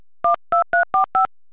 Mustererkennung,   Mehrfrequenzwahl, DTMF Signale
Für die Mehrfrequenzwahl beim Telefon benutzt man unterschiedliche Frequenzen, die im schmalbandigen Frequenzbereich der Telefonübertragungsnetze von 300 bis 3 kHz liegen müssen.
Beispiel: Die Taste 1 hat        697 Hz    und    1209 Hz.